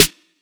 Snares